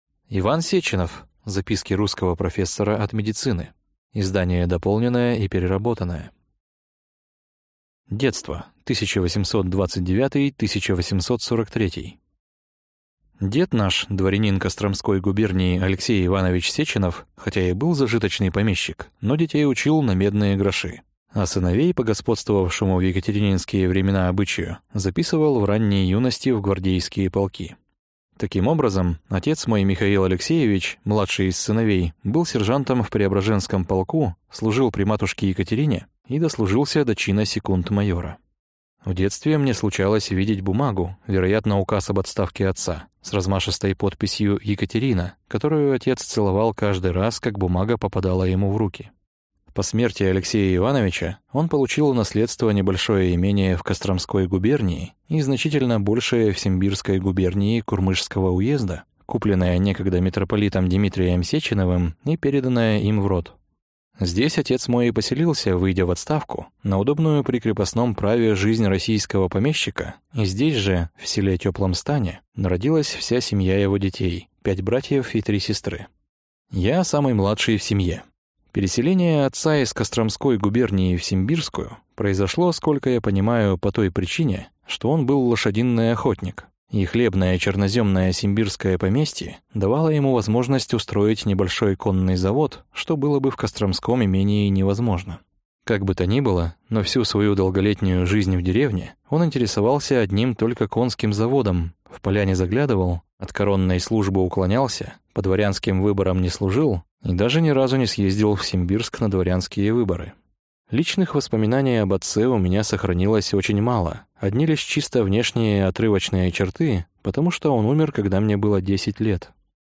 Аудиокнига Записки русского профессора от медицины | Библиотека аудиокниг